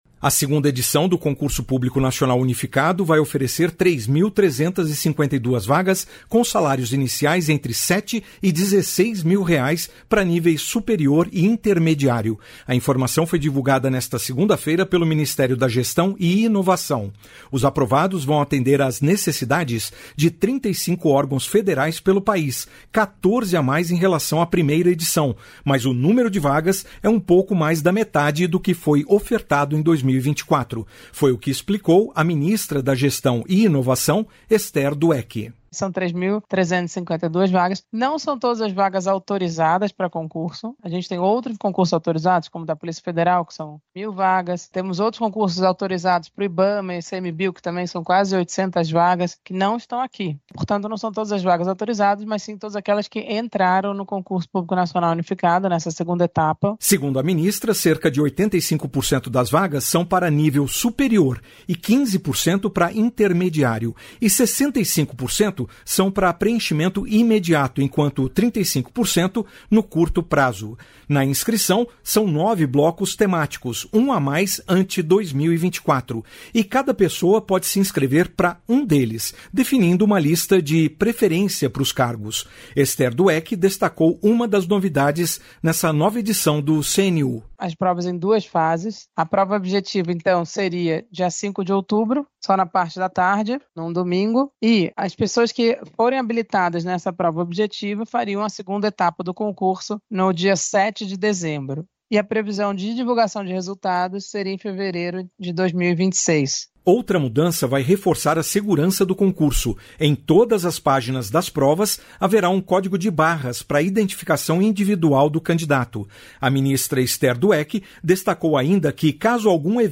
Foi o que explicou a Ministra da Gestão e Inovação, Esther Dweck.
Esther Dweck destacou uma das novidades nessa nova edição do CNU.